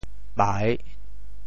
拜 部首拼音 部首 手 总笔划 9 部外笔划 5 普通话 bài 潮州发音 潮州 bai3 文 中文解释 拜 <动> (会意。
pai3.mp3